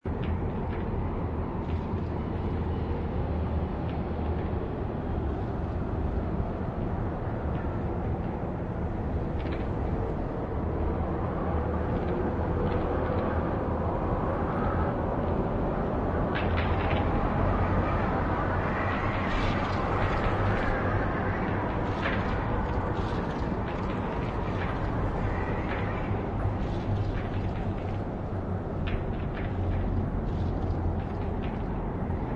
zone_field_mine_asteroid.wav